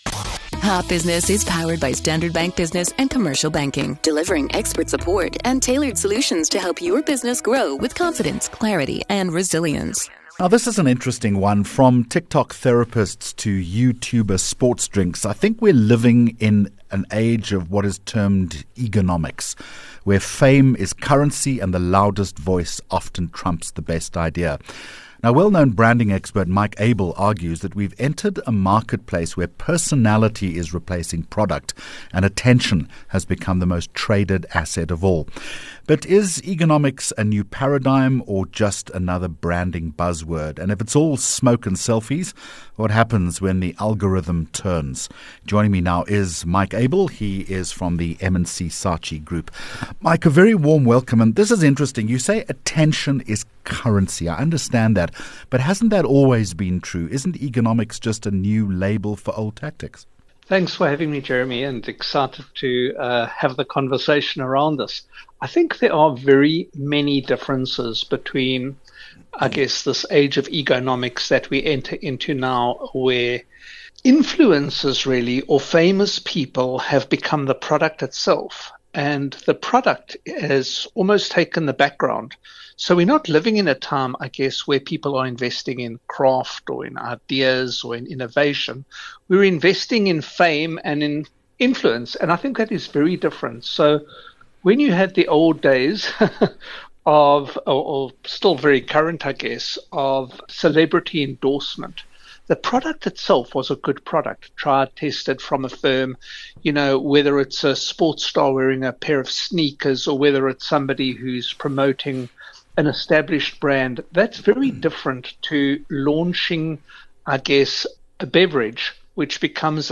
10 Jun Hot Business Interview